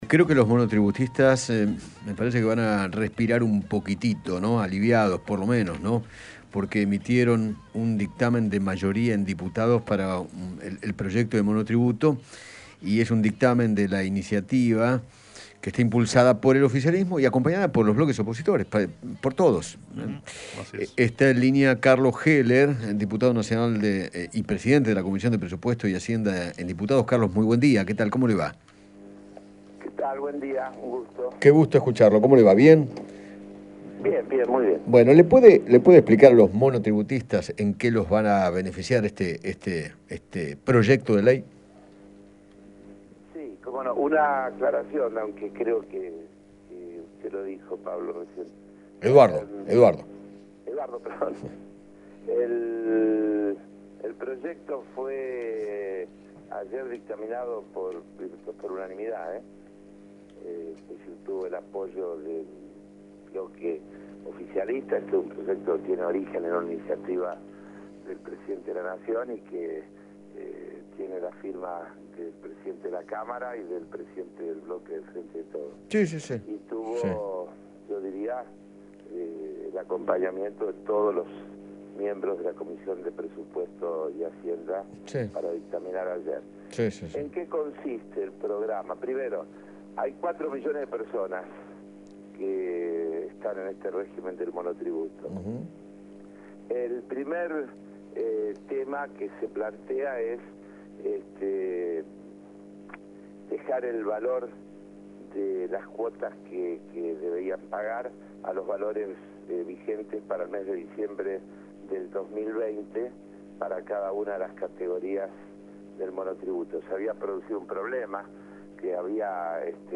El diputado nacional Carlos Heller dialogó con Eduardo Feinmann sobre las principales modificaciones propuestas en el proyecto de ley del monotributo, que comenzarían a regir el 1° de julio.